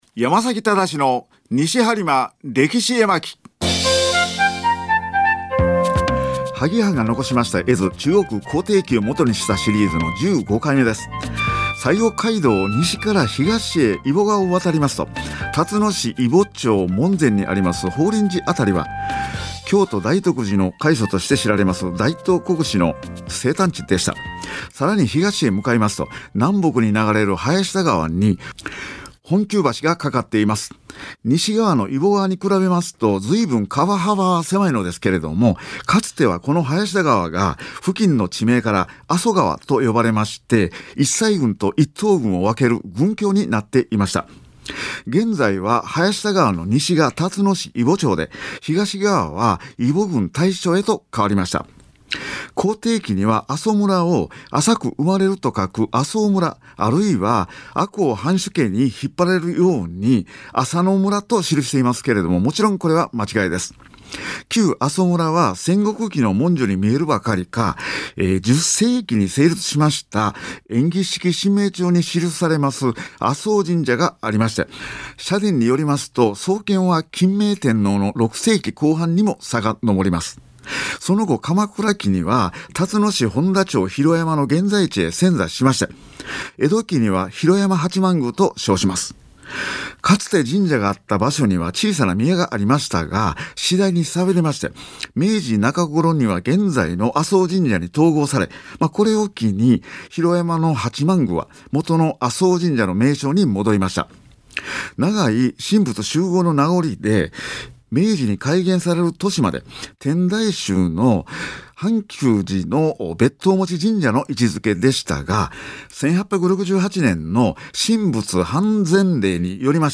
2020年10月20日放送回音声